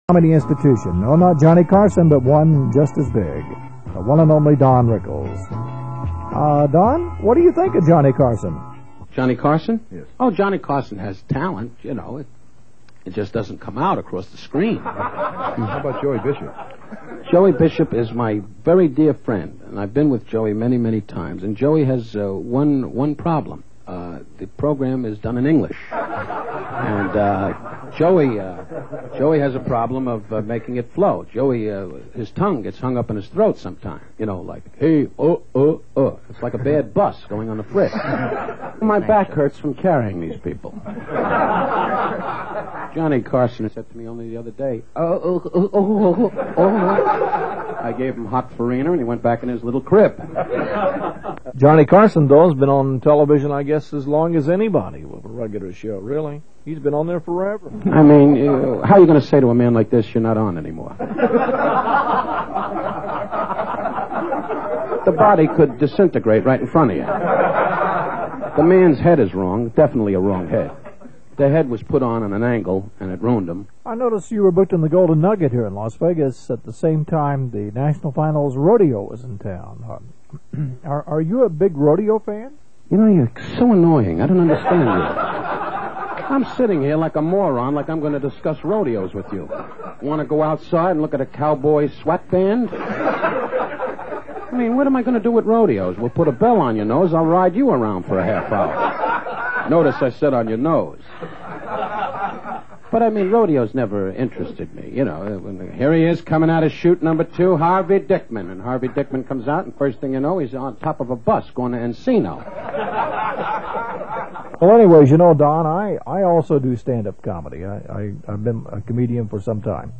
Don Rickles  Interview.wma